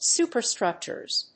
発音記号
• / ˈsupɝˌstrʌktʃɝz(米国英語)
• / ˈsu:pɜ:ˌstrʌktʃɜ:z(英国英語)